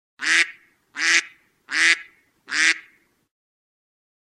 Duck Sound Iphone